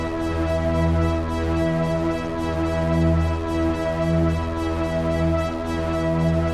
Index of /musicradar/dystopian-drone-samples/Tempo Loops/110bpm
DD_TempoDroneD_110-E.wav